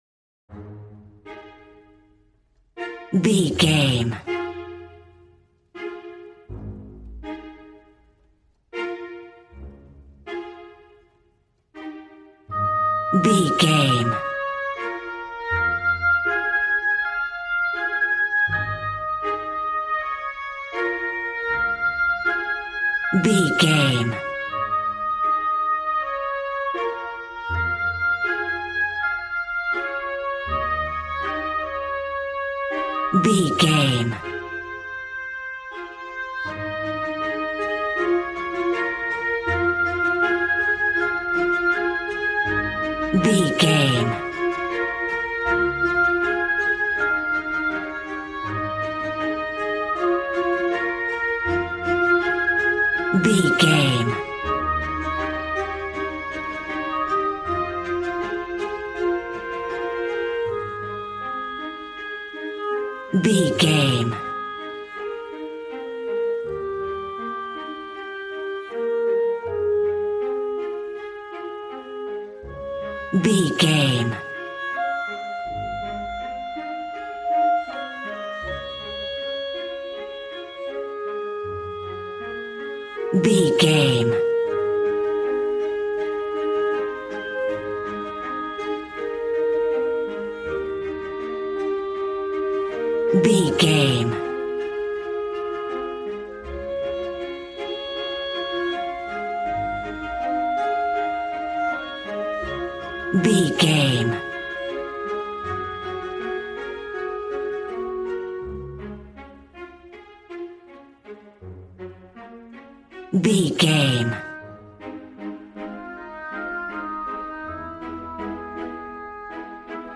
A warm and stunning piece of playful classical music.
Regal and romantic, a classy piece of classical music.
Ionian/Major
A♭
regal
piano
violin
strings